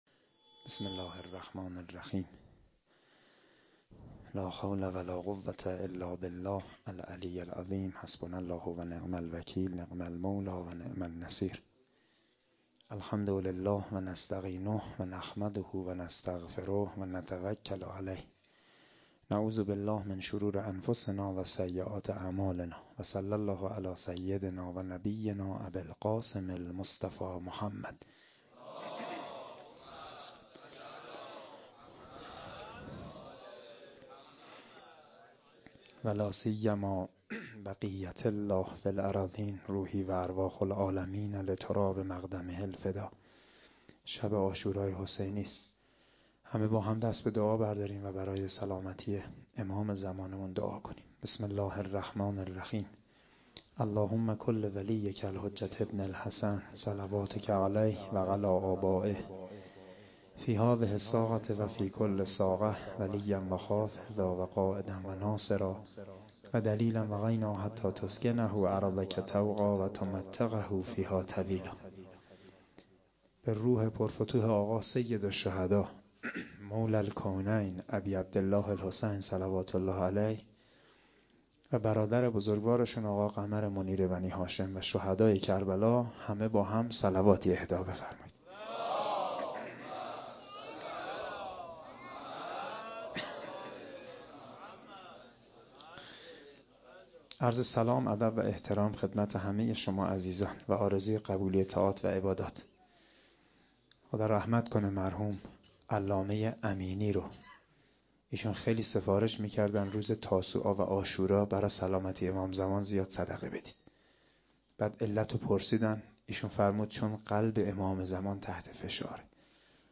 01-shabe10-sokhanrani.mp3